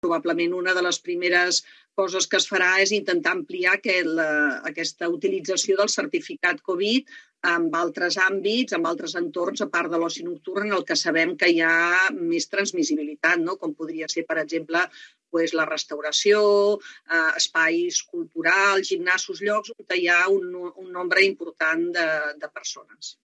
En una entrevista al 324